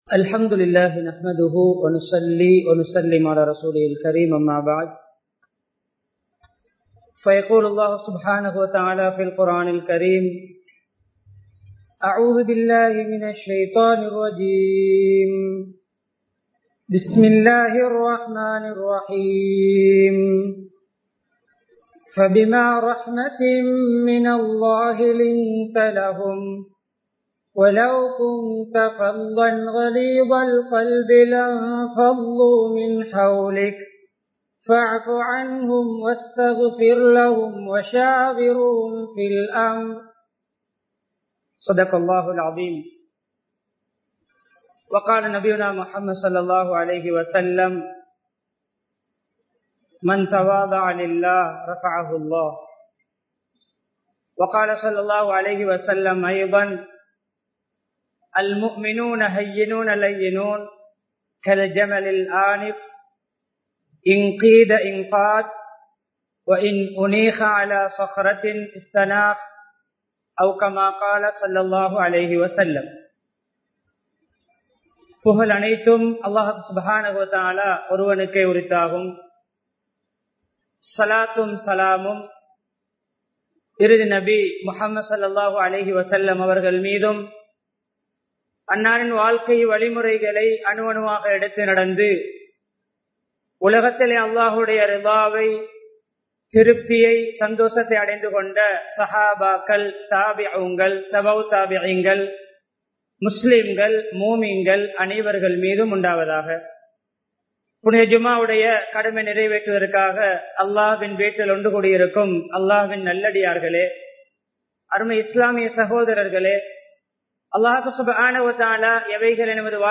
நபியவர்களின் கவர்ச்சிகரமான ஆளுமை (Attractive Personality of Prophet(SAW)) | Audio Bayans | All Ceylon Muslim Youth Community | Addalaichenai
Thalduwa, Town Jumua Masjidh